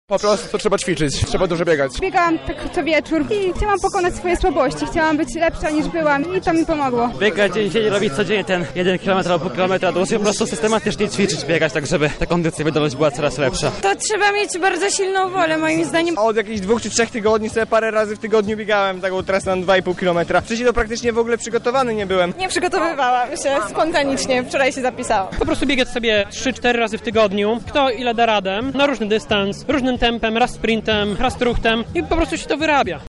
Zapytaliśmy biegaczy, jak przygotowywali się do zawodów